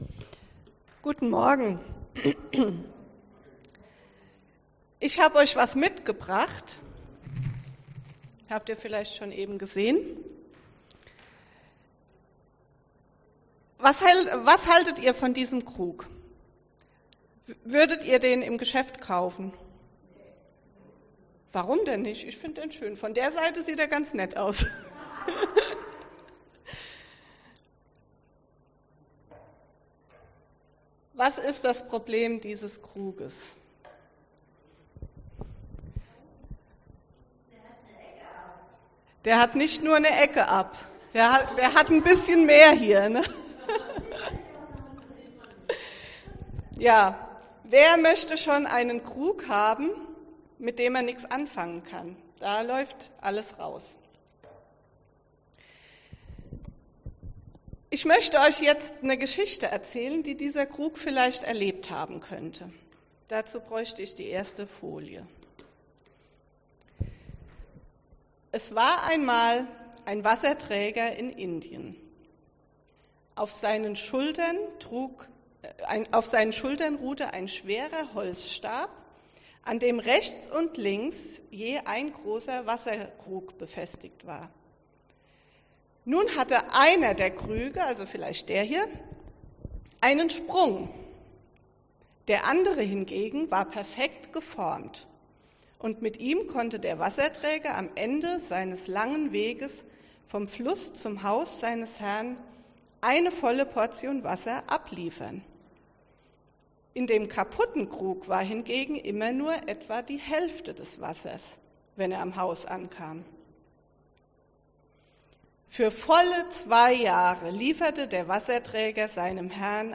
Bibeltext zur Predigt: Kol. 2,11-15